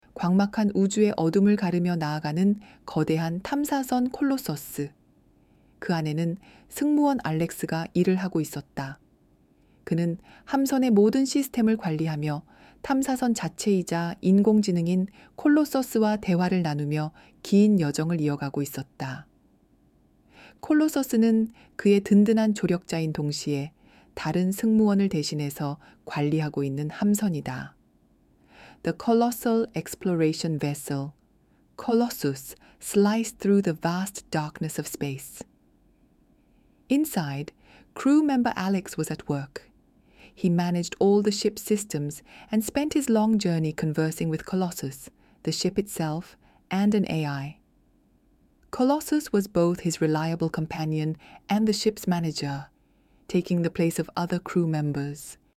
Voice Design(보이스 디자인)이라는 기능을 출시했습니다. 이 기능은 프롬프트만으로 목소리를 생성해주는 서비스입니다.
이렇게 생성한 목소리 몇 가지를 들어보겠습니다.